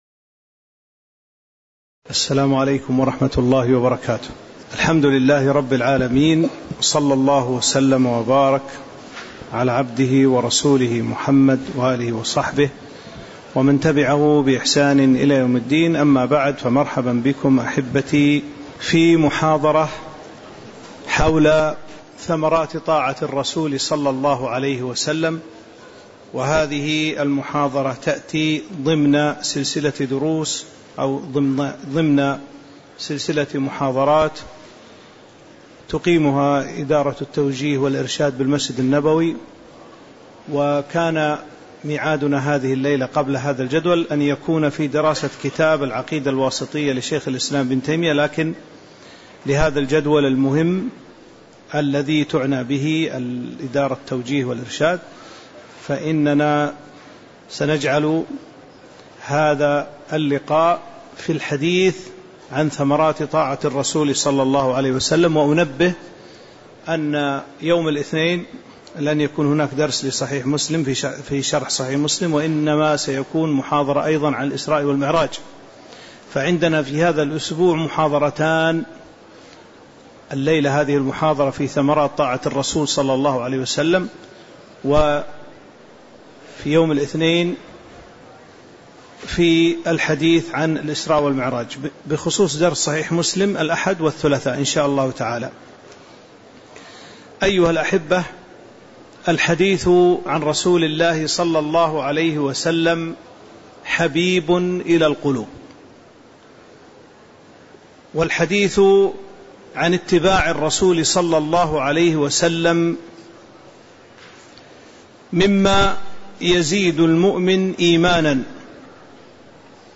تاريخ النشر ٢٠ رجب ١٤٤٤ هـ المكان: المسجد النبوي الشيخ